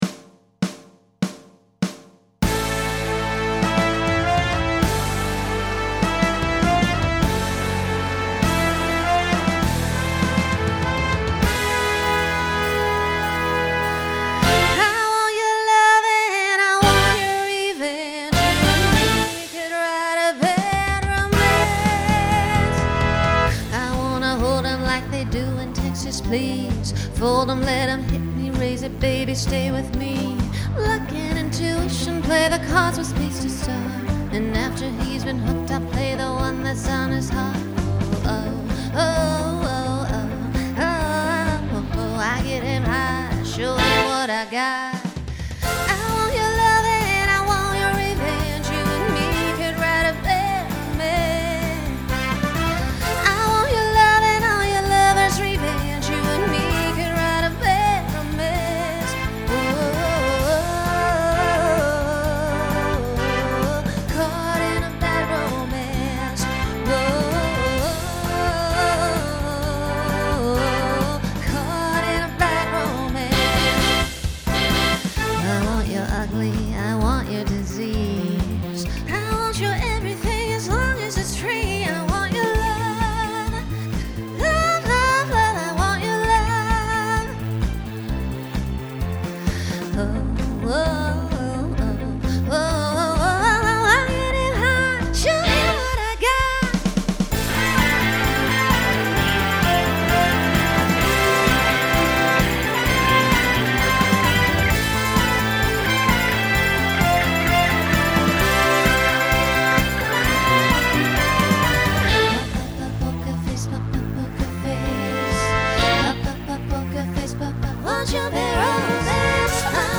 Begins with an extended solo to facilitate costume change.
Genre Pop/Dance
Transition Voicing SSA